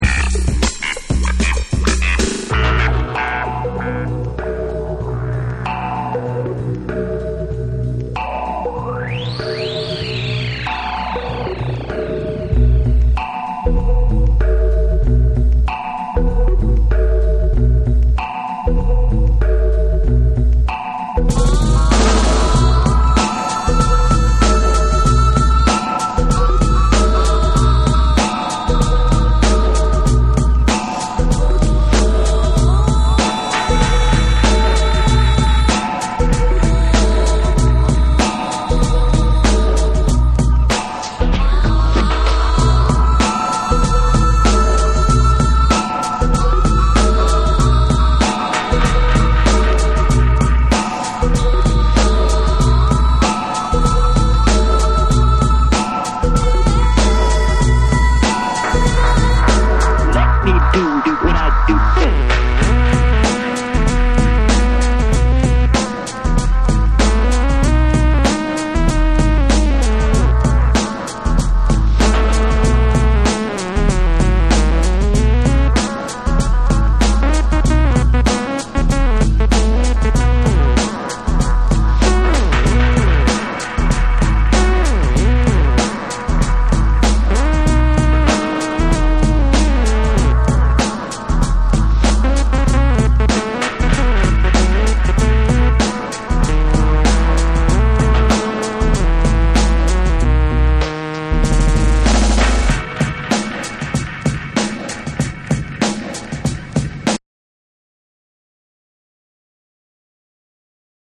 BREAKBEATS / ORGANIC GROOVE